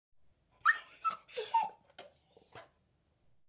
Whimper1.wav